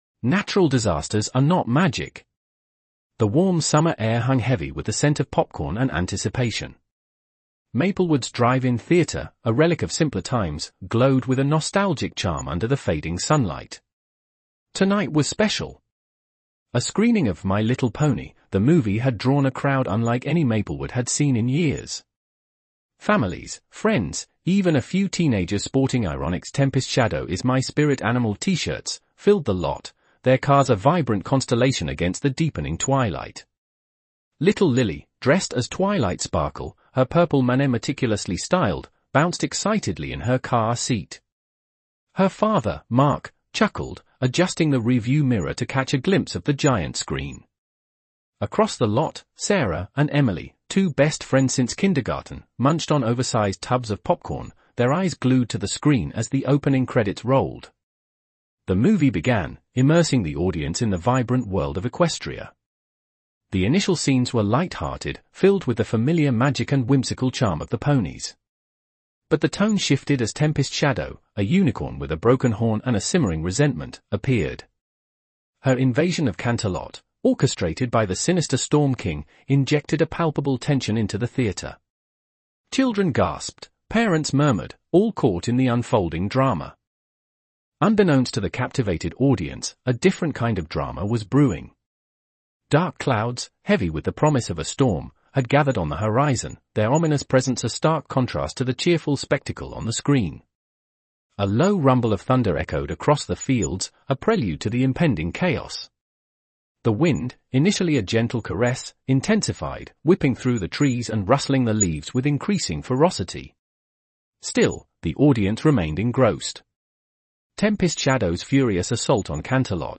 fanfiction story
Text-to-speech voice